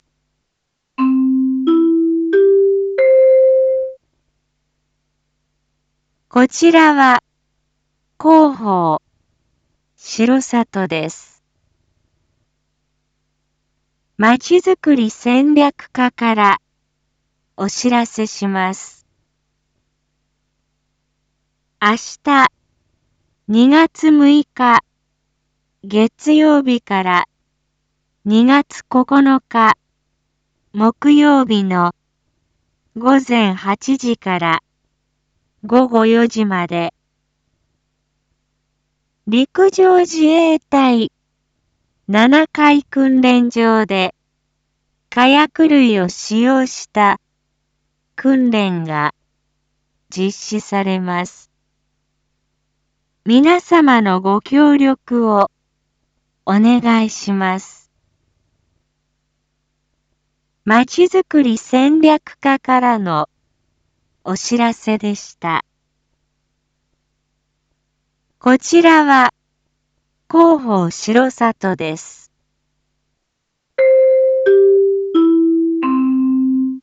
一般放送情報
Back Home 一般放送情報 音声放送 再生 一般放送情報 登録日時：2023-02-05 19:01:18 タイトル：R5.2.5 19時放送分 インフォメーション：こちらは広報しろさとです。